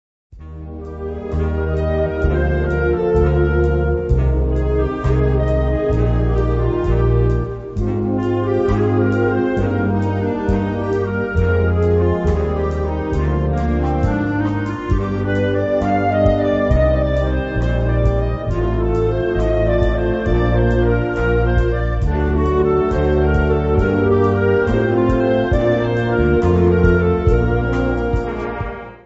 Categorie Harmonie/Fanfare/Brass-orkest
Bezetting Ha (harmonieorkest); [ (optional); Rock-Band; ]
Instrumentatie/info Solo f. Rock-Band (opt.)